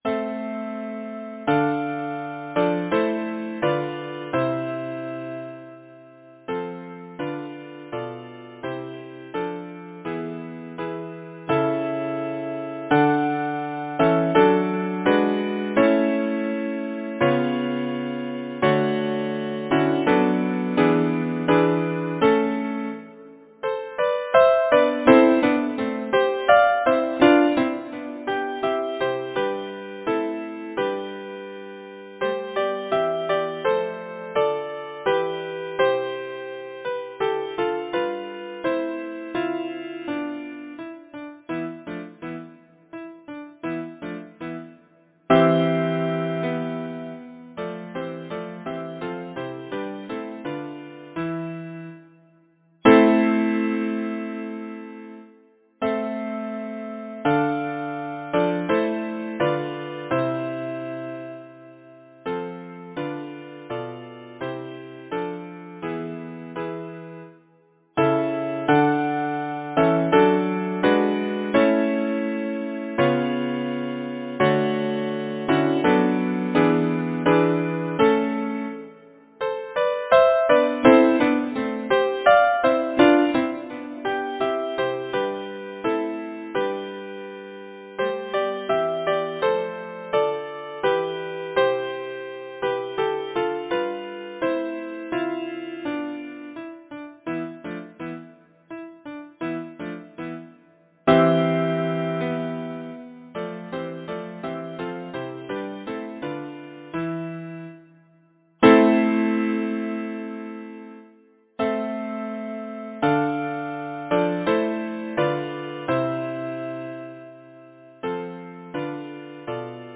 Title: The patient lover Composer: Charles Villiers Stanford Lyricist: May Byron Number of voices: 4vv Voicing: SATB Genre: Secular, Partsong
Language: English Instruments: A cappella